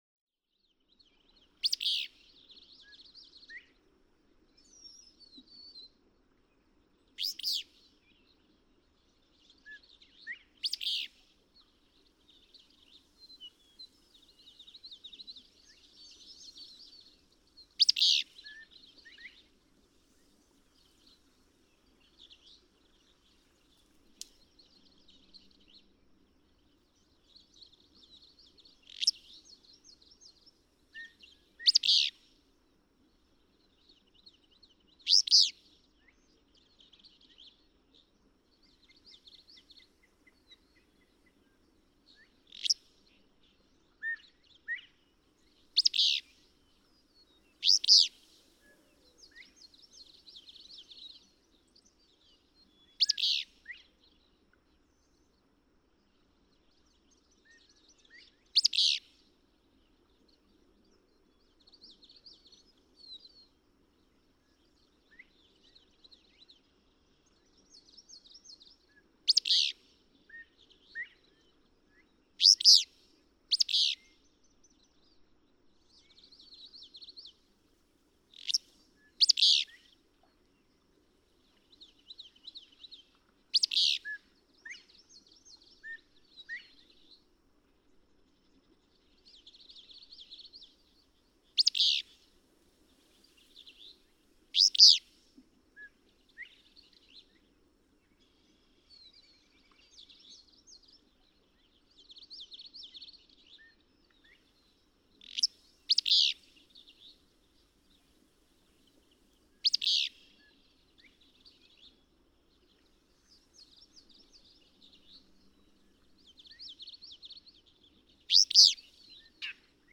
Willow flycatcher
♫576. Typical singing of an "eastern" willow flycatcher. June 2, 2008. Prairie State Park, Mindenmines, Missouri. (3:51)
576_Willow_Flycatcher.mp3